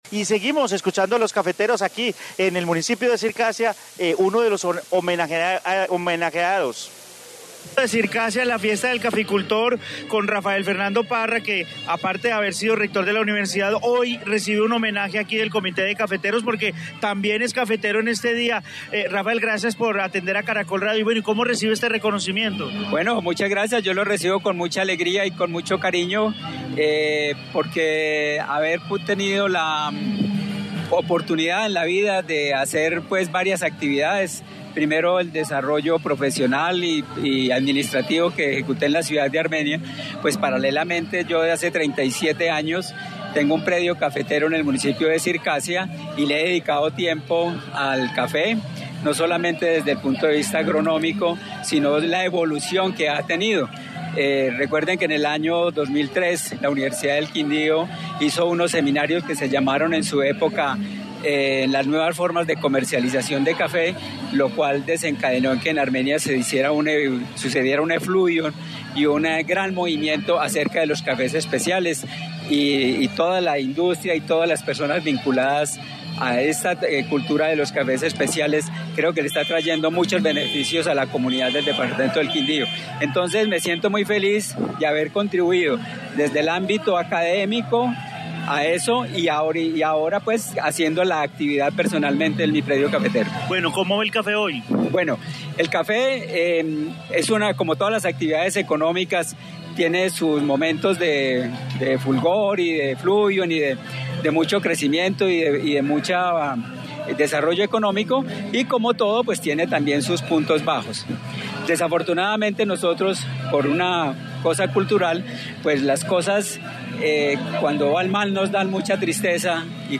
En el coliseo del colegio Libre de Circasia se llevó a cabo la fiesta del caficultor en el día nacional del café
Informe fiesta del caficultor en Circasia, Quindío